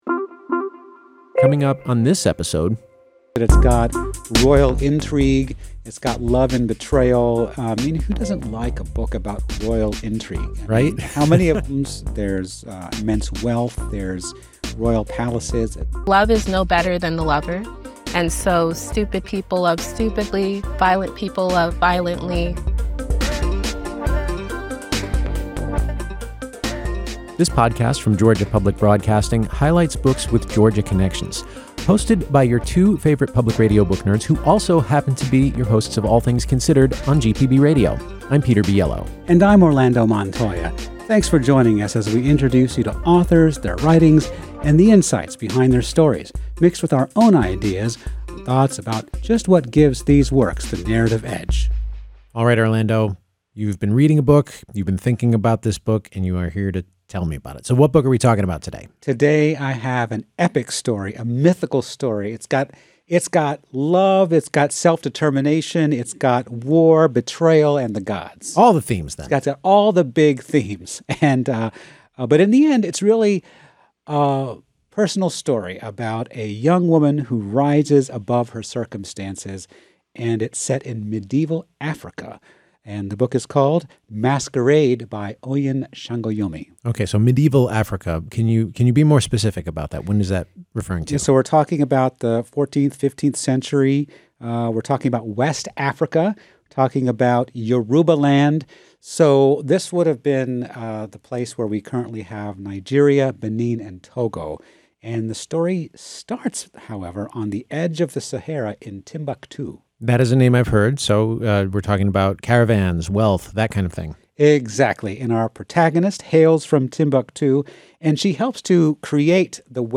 … continue reading 54 episodes # Society # Books # Arts # Georgia Public Broadcasting # Lifestyle # Hobbies # Read # Review # NPR # GPB # Interviews # Authors